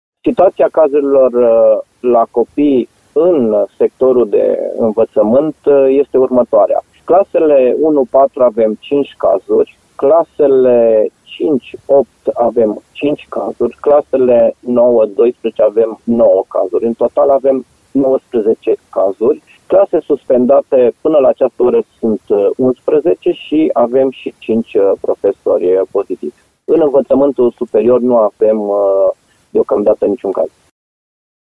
Șeful Direcției de Sănătate Publică, Horea Timiș.